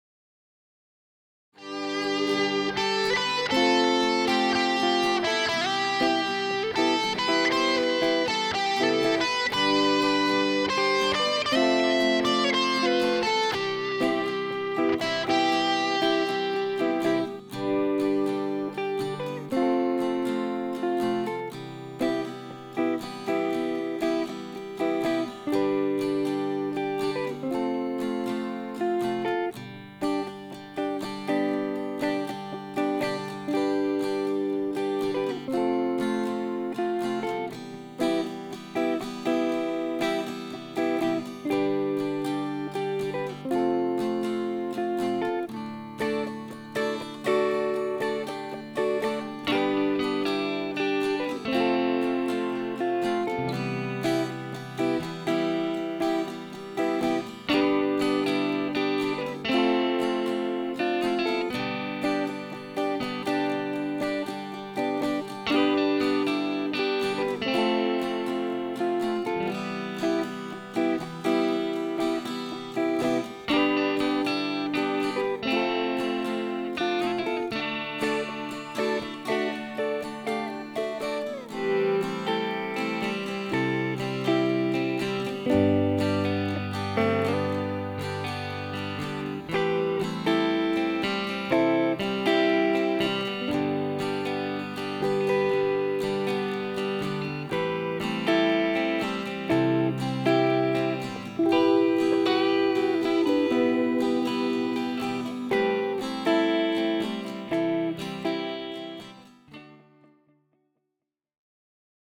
pieza para guitarra clásica